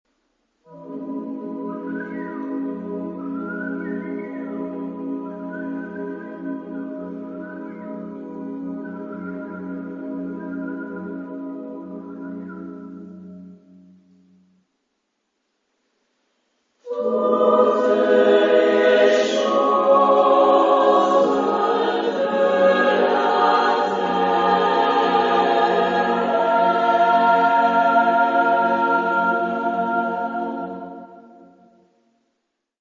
Género/Estilo/Forma: Profano ; Poema ; contemporáneo
Tonalidad : politonal